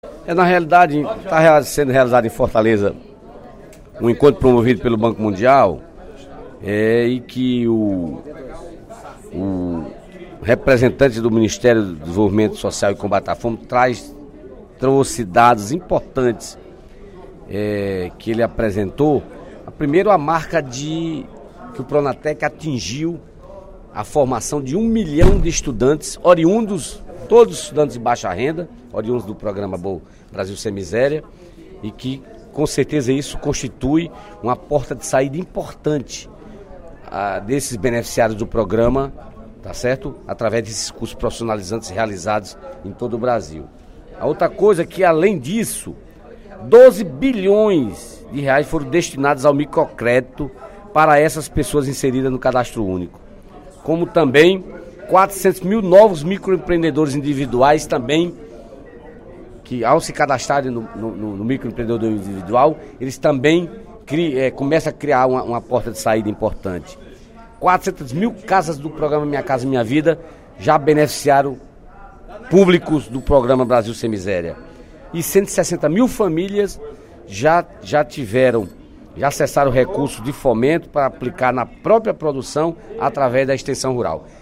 O deputado Dedé Teixeira (PT) fez pronunciamento nesta quinta-feira (13/03), durante o primeiro expediente da sessão plenária da Assembleia Legislativa, para informar sobre a realização, em Fortaleza, do Encontro da Comunidade de Aprendizagem em Transferências Monetárias da África.